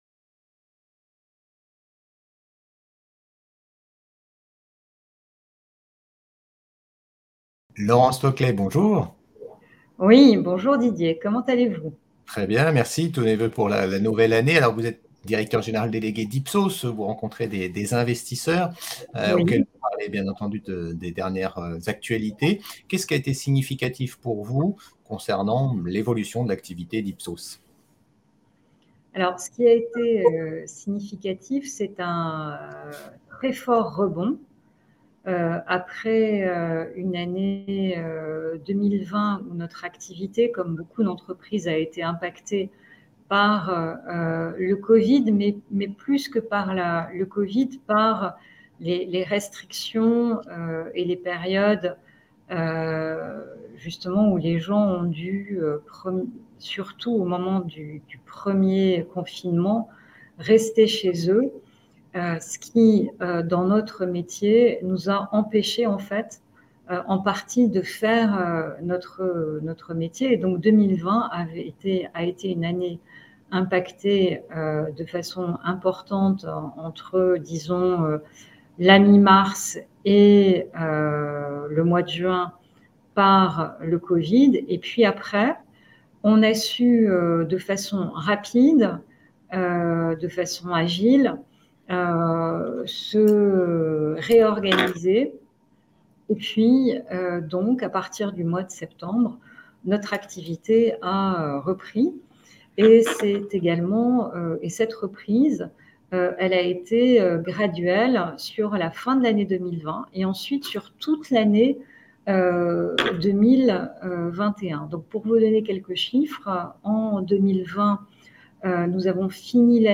25th Forum Oddo BHF